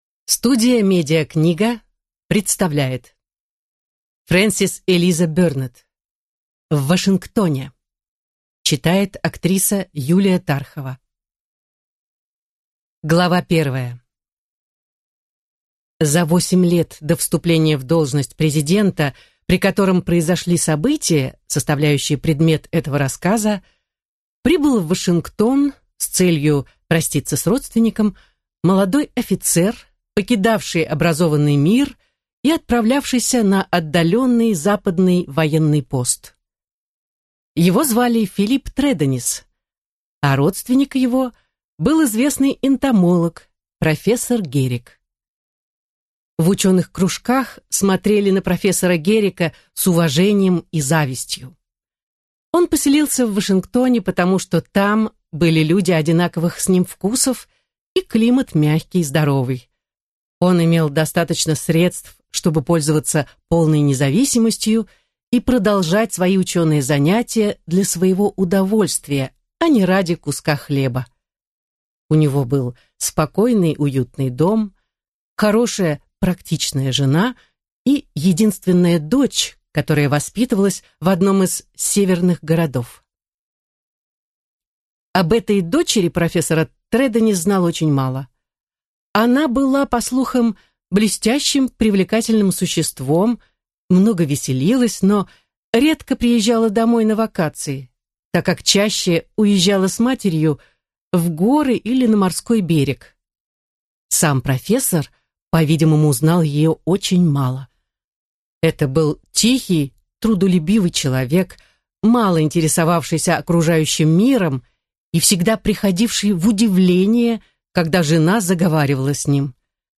Аудиокнига В Вашингтоне | Библиотека аудиокниг